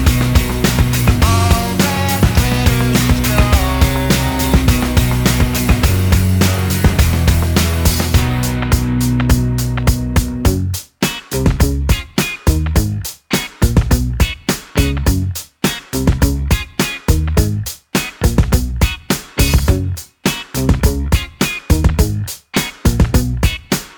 no Backing Vocals Rock 3:21 Buy £1.50